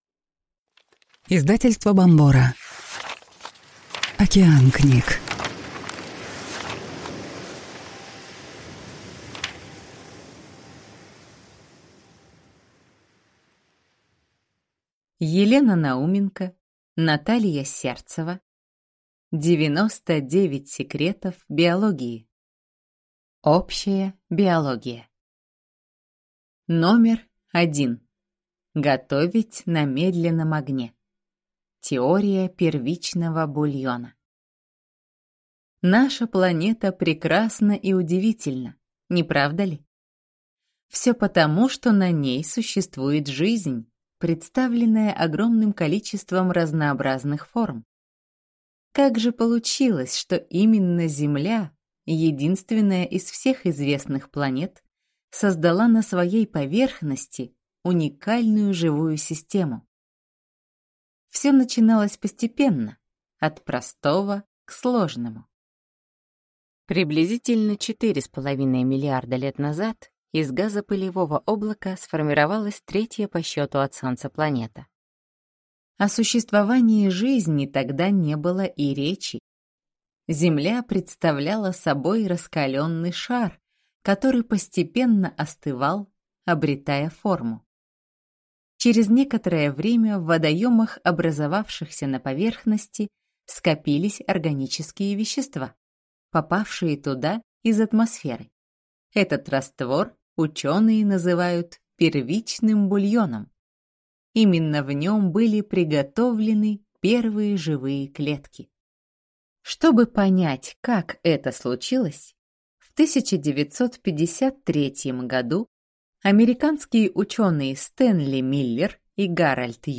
Аудиокнига 99 секретов биологии | Библиотека аудиокниг